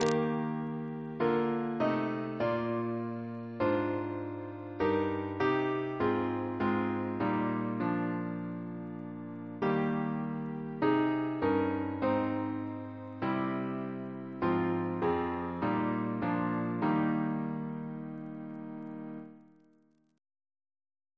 Double chant in E♭ Composer: Hezekiah West (d.1826) Reference psalters: ACP: 317; RSCM: 160